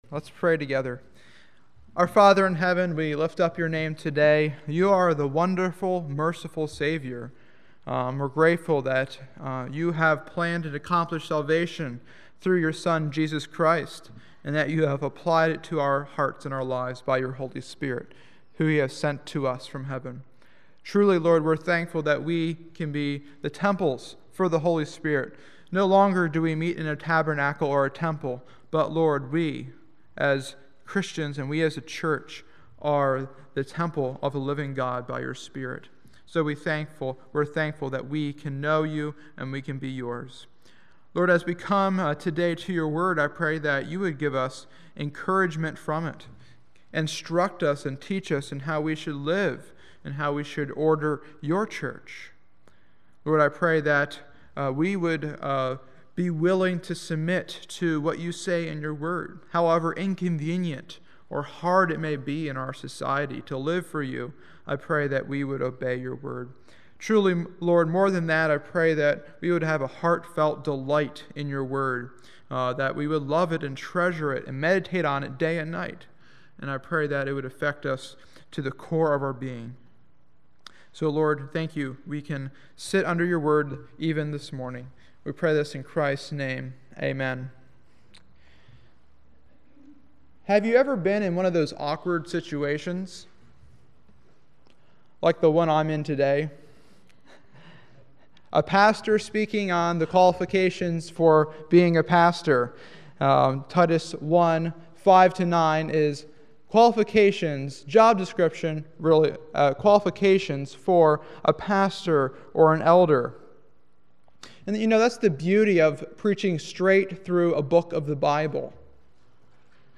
Sermons List